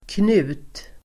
Uttal: [knu:t]